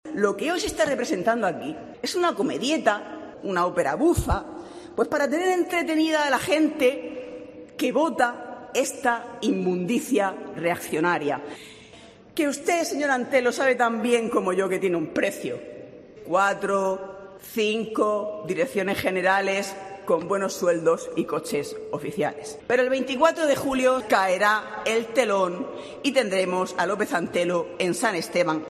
María Marin, portavoz del Grupo Parlamentario Podemos en la Asamblea Regional